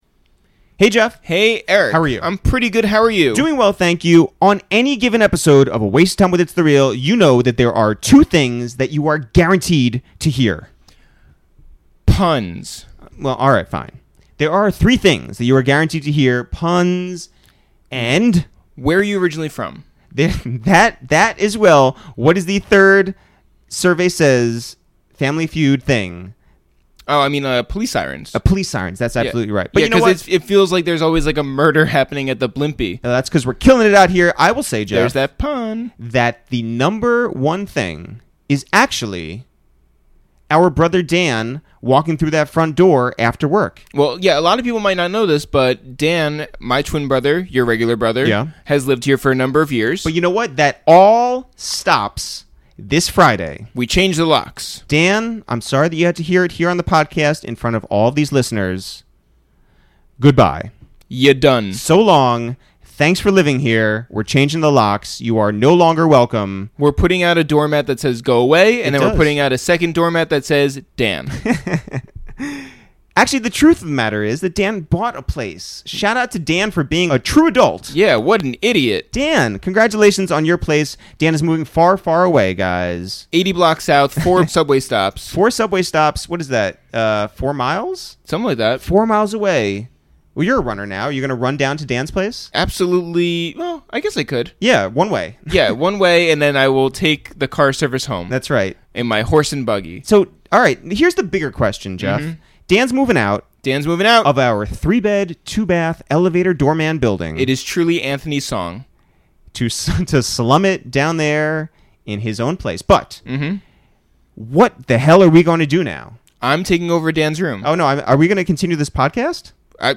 Tierra, in her first extended interview, go into depth on growing up in Philadelphia with her mother and two younger siblings, auditioning to get into an arts high school an hour away, her profecientcy for singing and visual arts, how much her principal hated hip-hop, the genesis of Dizzle Dizz, meeting Meek Mill, and how she ended up on Cosmic Kev's radio show, freestyling for fourteen minutes.